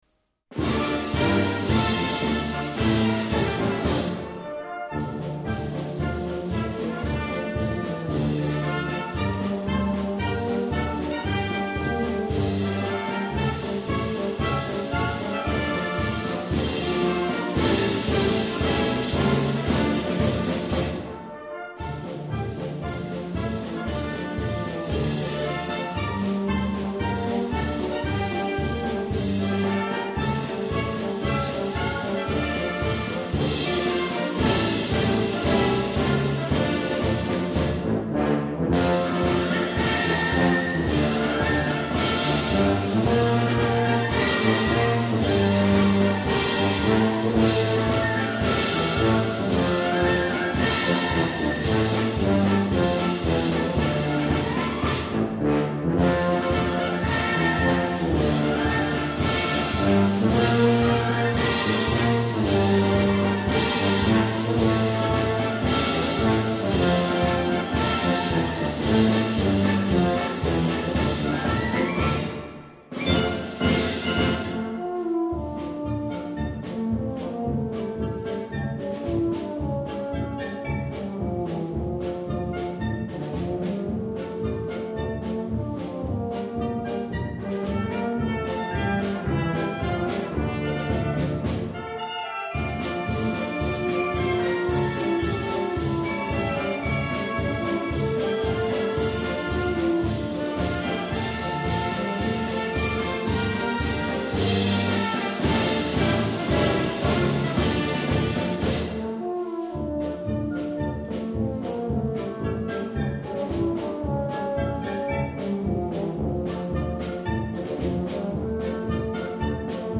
Это старинный дореволюционный марш "Привет Музыкантам" - (Неизвестный автор) - с сайта Военные марши - послушайте и сравните....
starinnyiy-marsh-privet-muzyikantam.mp3